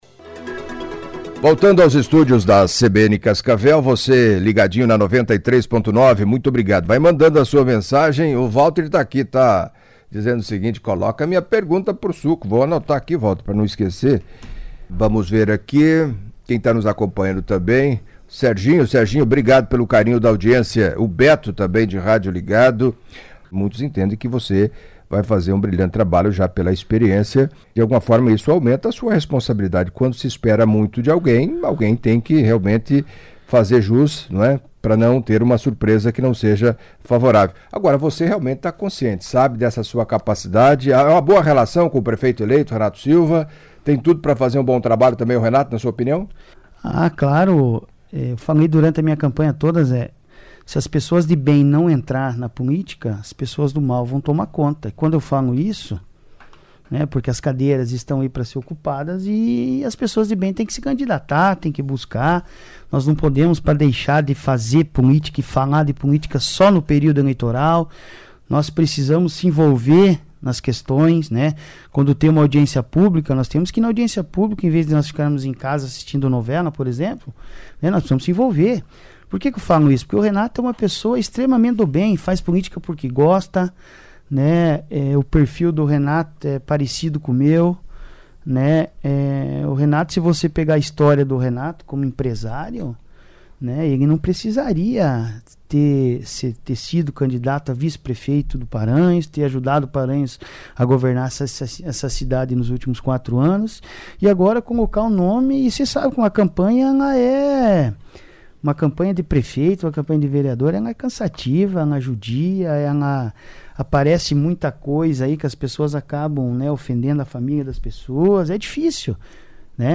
Em entrevista à CBN Cascavel nesta quinta-feira (28) Alexandre Guerino, popular Suco, vereador eleito do PSD com 1962 votos, inicialmente destacou a liberação de recursos por parte do governo do estado para revitalização do estádio Ninho da Cobra.